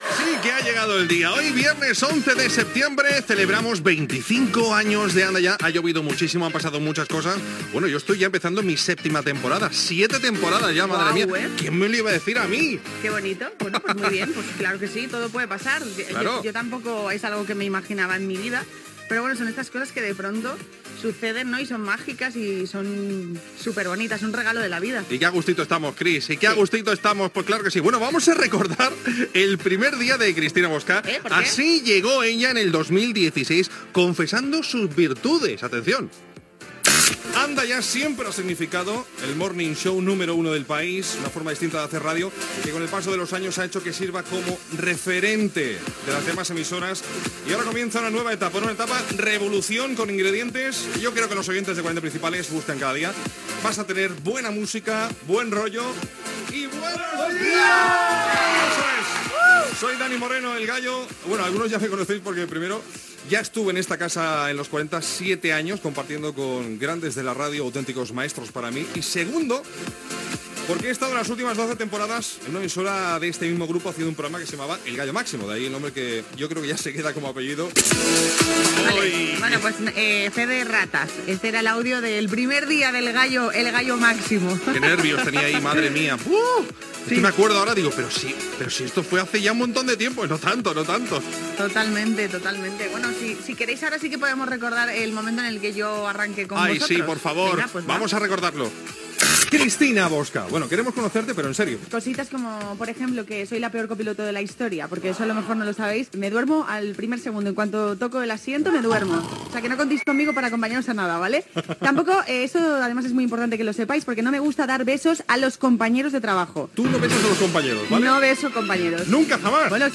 Entreteniment
FM